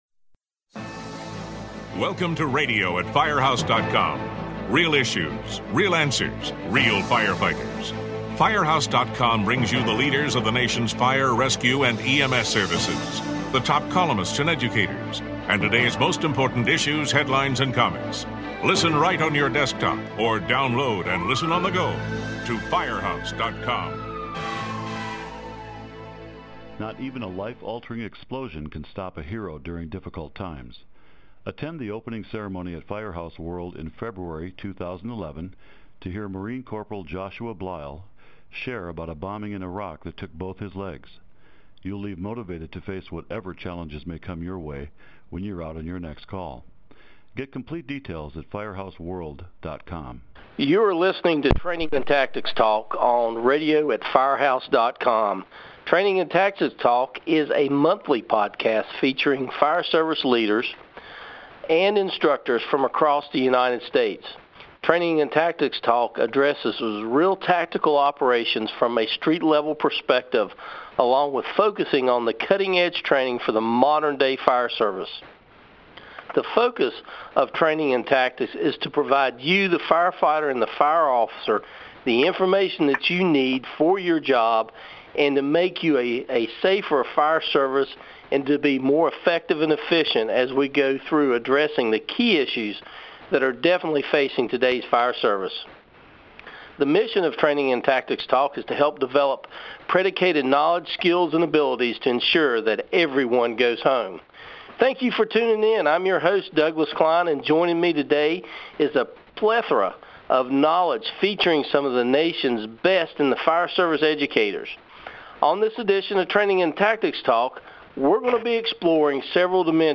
talks with his guests from across the United States